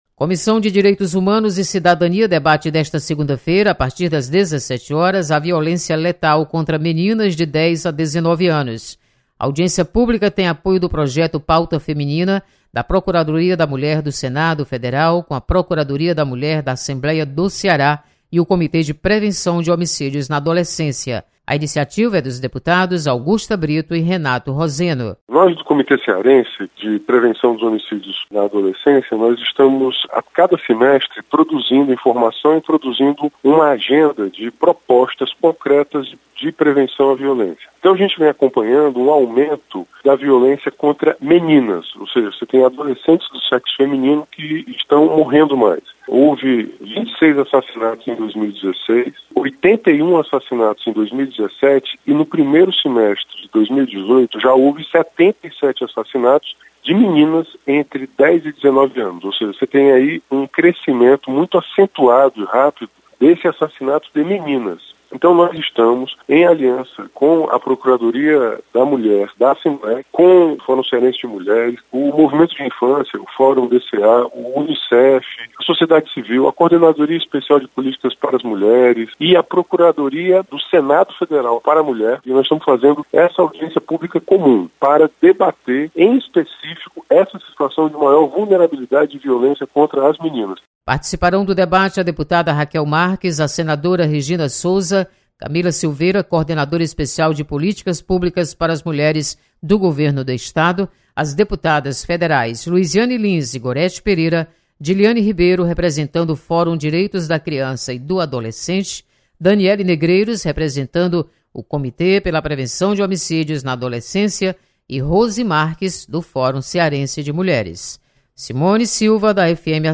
Comissão de Direitos Humanos debate violência contra meninas. Repórter